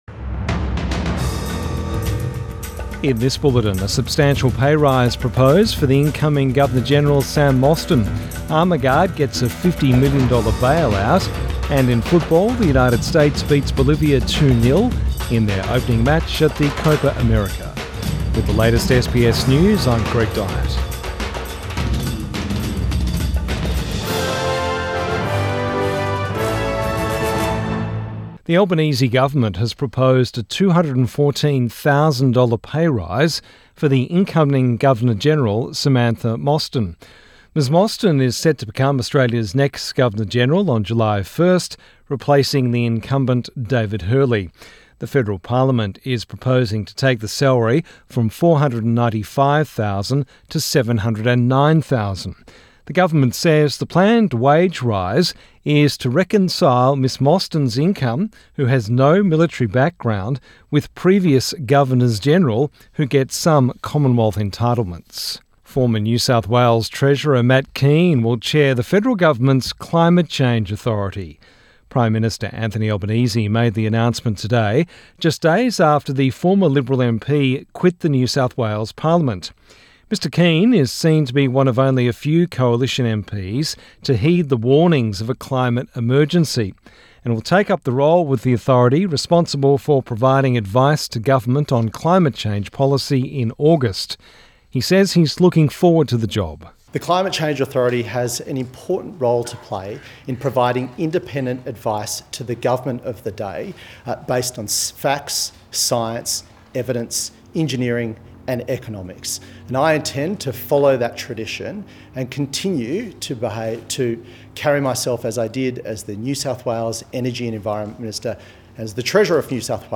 Evening News Bulletin 24 June 2024